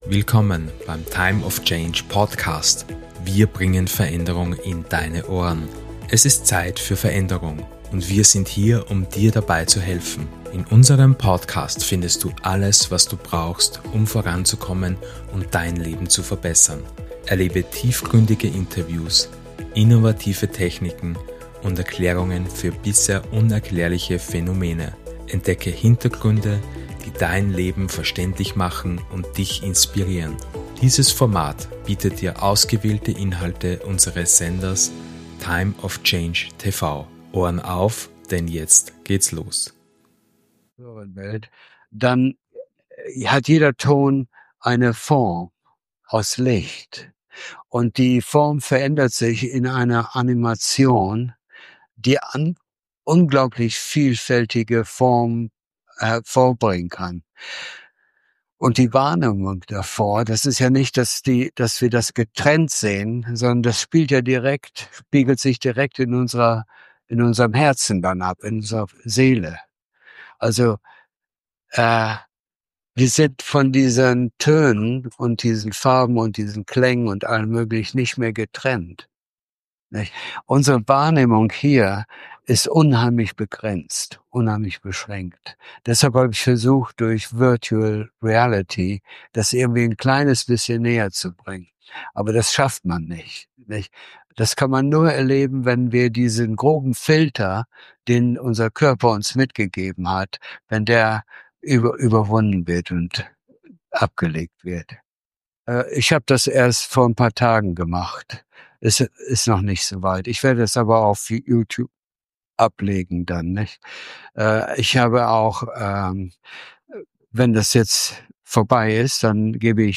Astralwelten